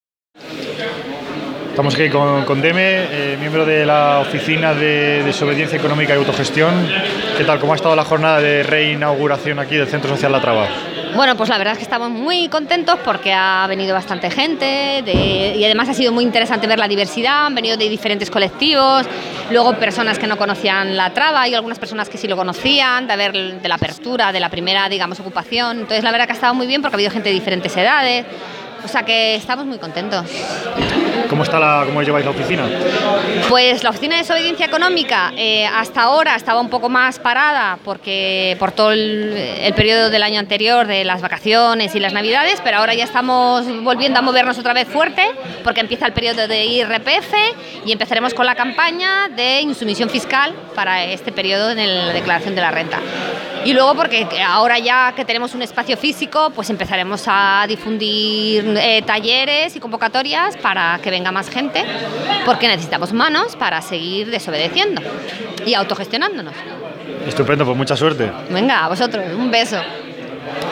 Entrevistas durante la re-inauguración del CSO La Traba
Entrevista